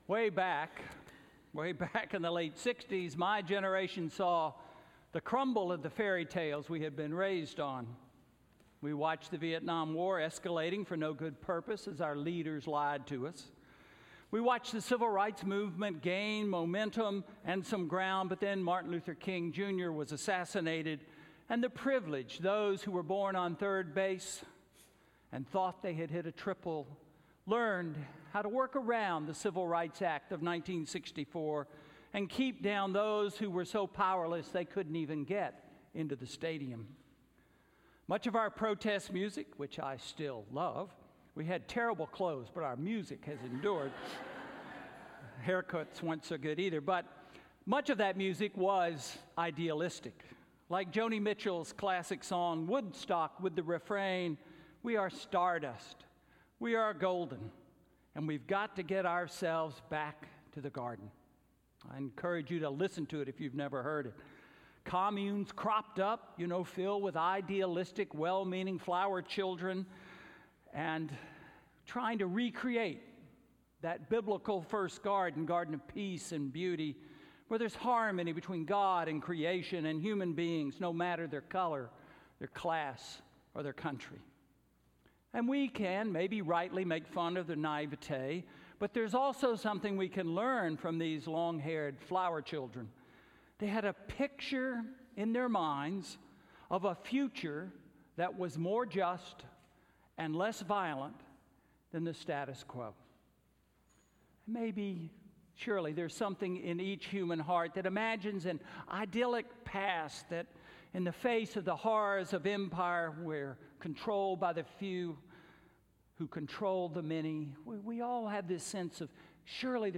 Sermon–Back to the Garden or the City? May 26, 2019